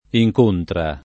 incontra [ i j k 1 ntra ]